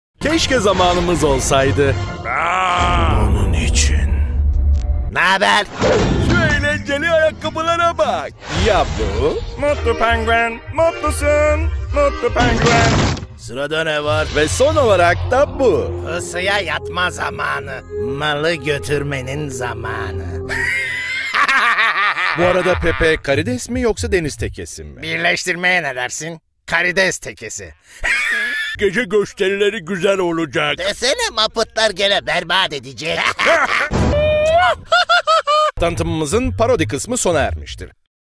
Sprecher türkisch.
Sprechprobe: Sonstiges (Muttersprache):